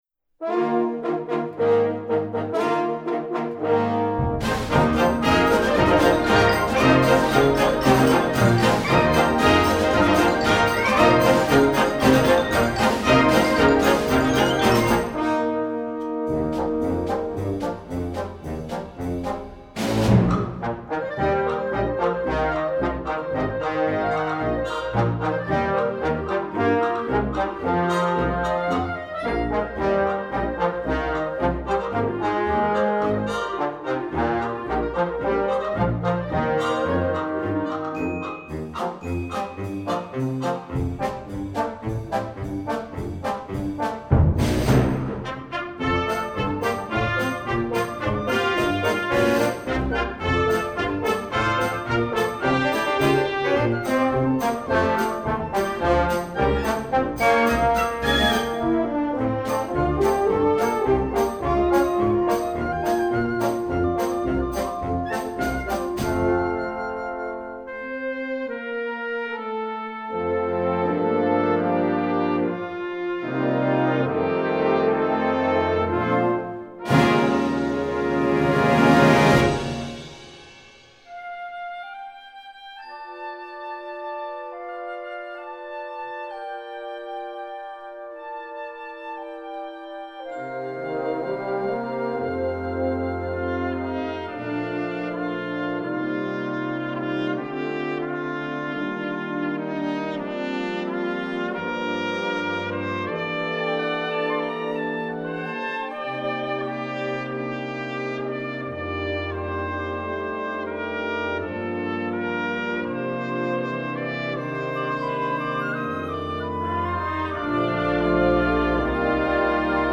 pop, children, instructional